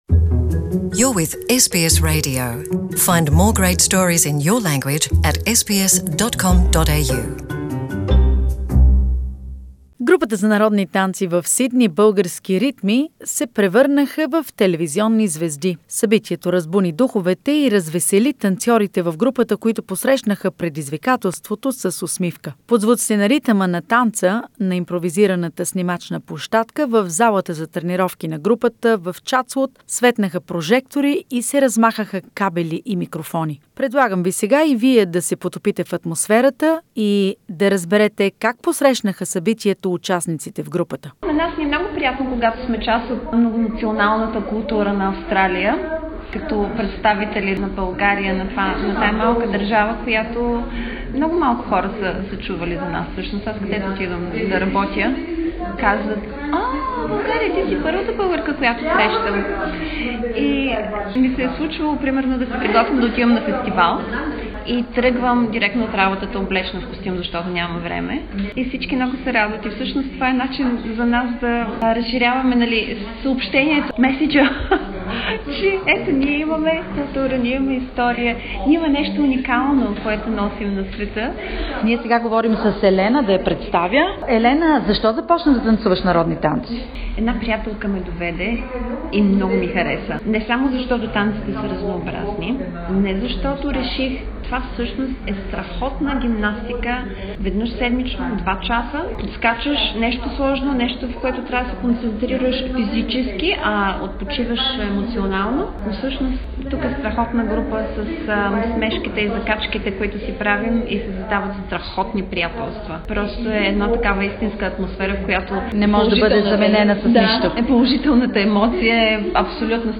Една незабравима вечер с много емоции, няколко прожектора и микрофони преживяха групата за фолклорни танци в Сидни "Български ритми". Как се справиха с моментната си слава - чуйте в репортажа